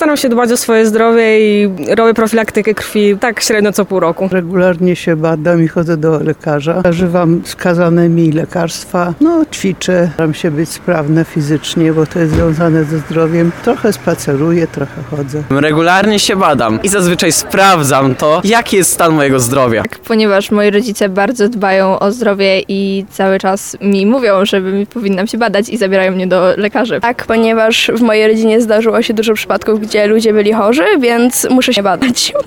Sami tarnowianie przyznają, że starają się dbać o zdrowie.
7sonda-zdrowie.mp3